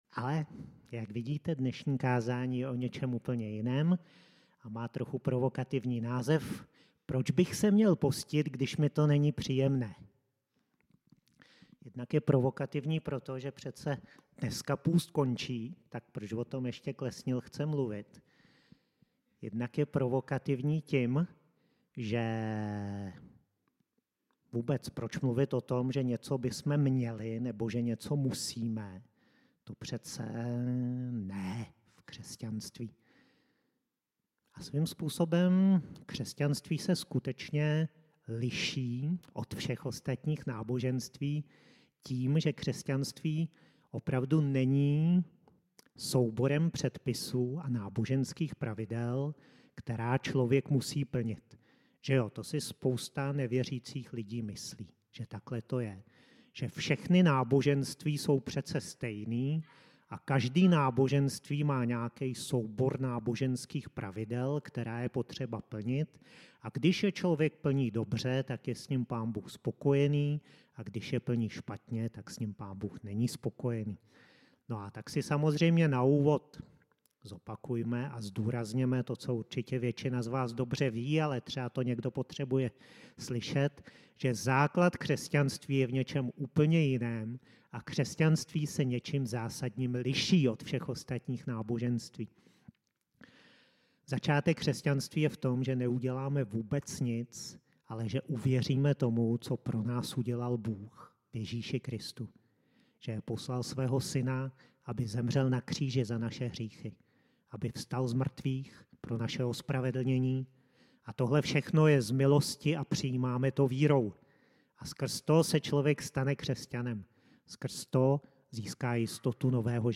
Kázání pro snižující se počet křesťanů, kteří hledají něco více než své příjemné pocity